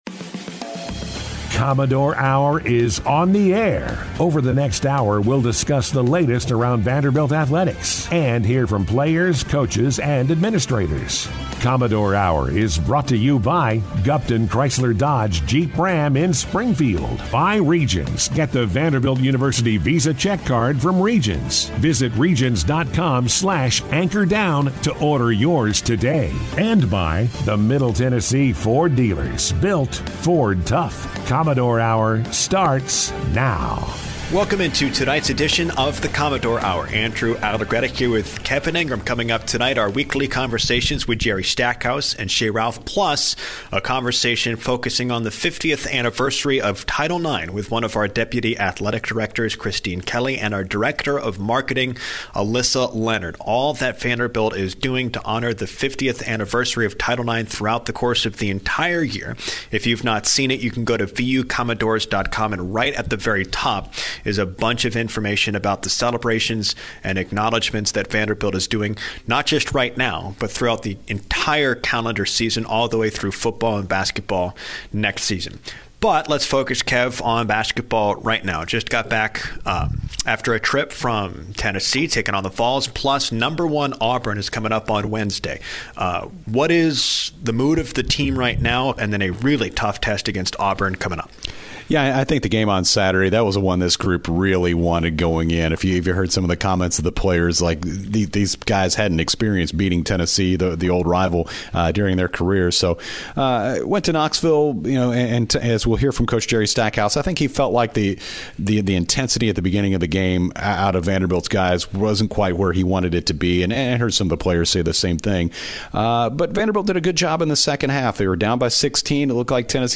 Guests on this week's Commodore Hour, Mondays from 6-7 PM on ESPN 94.9: